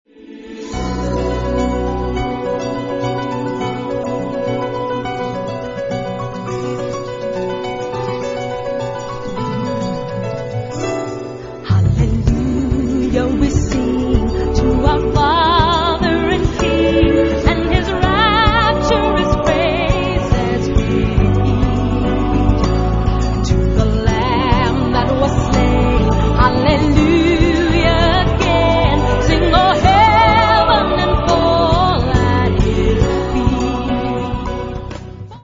Genre-Style-Form: Sacred ; Gospel ; Spiritual
Mood of the piece: beautiful ; lively
Type of Choir: SATB  (4 mixed voices )
Soloist(s): Mezzo-soprano (1)  (1 soloist(s))
Instruments: Piano (1) ; Bass (1) ; Trap set (1)
Tonality: D major